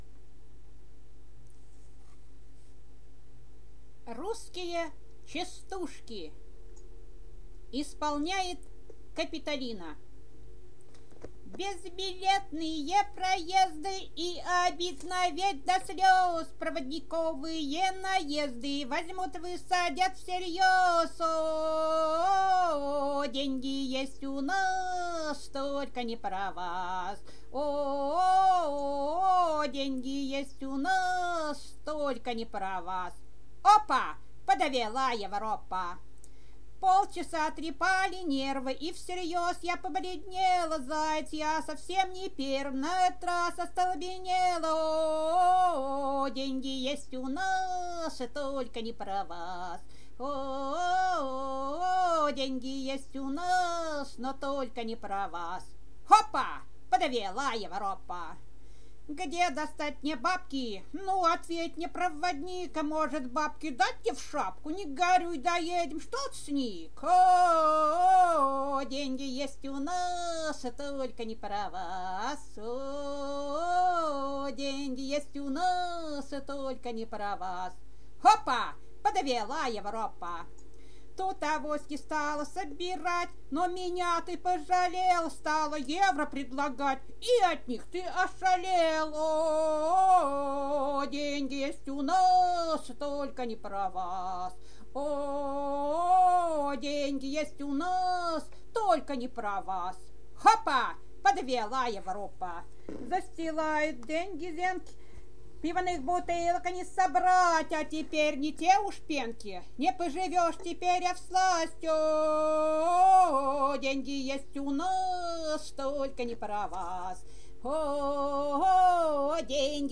Частушки